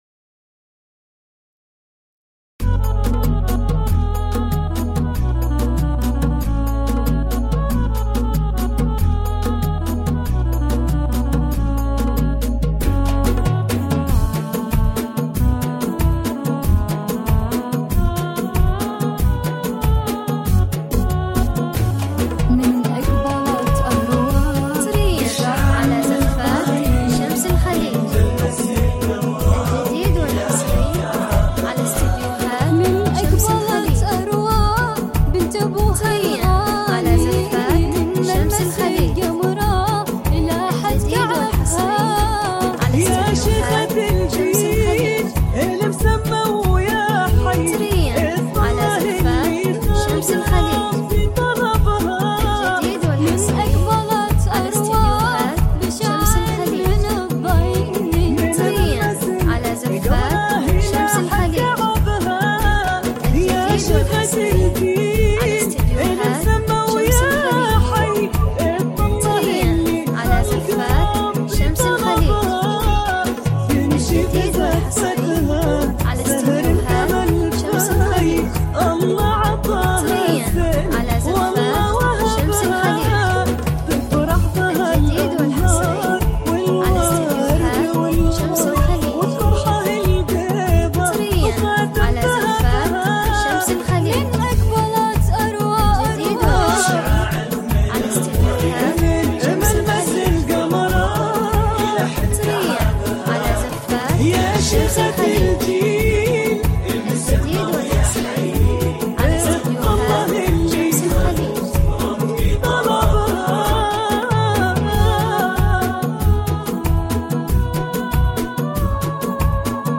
زفات بدون موسيقى